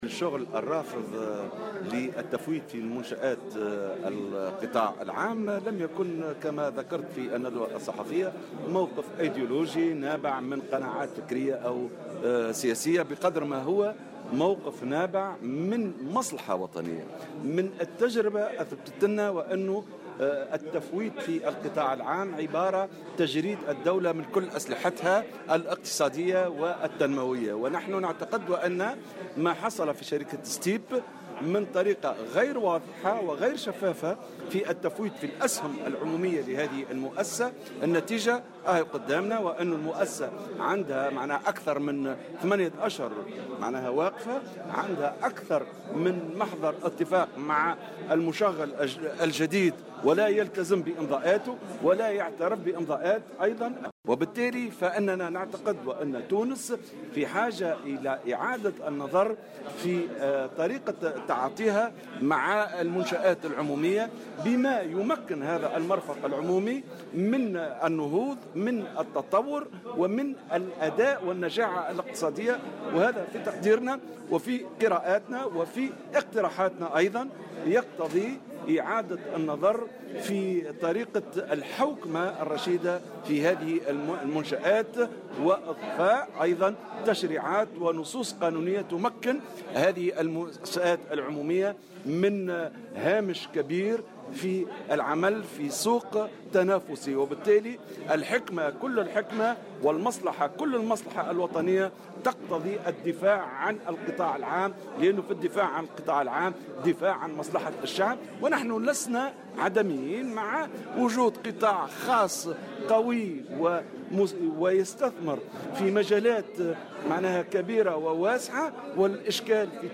وأضاف في تصريح لمراسل "الجوهرة أف ام" أن ما يحصل اليوم في الشركة التونسية لصناعة الإطارات المطاطية "ستيب" التي توقفت عن النشاط منذ أكثر من 8 أشهر نظرا لعدم تعهّد المشغّل الجديد بتعهداته يفرض على الحكومة إعادة النظر في طريقة تعاطيها مع المنشآت العمومية بما يمكّنها من النهوض والنجاعة الاقتصادية.